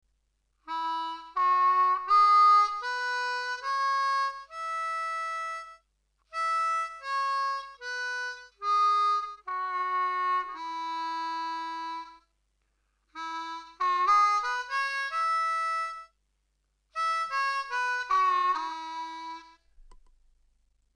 For now though, we’re using a regular Richter tuned 10 hole diatonic harmonica.
We’re in cross harp (second position) on an A harmonica – key of E major.
Lower Octave Country Scale
Country-Scale-Mid-Octave.mp3